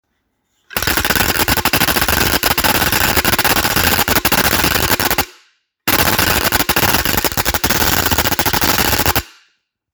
Carraca 4 tonos
De madera.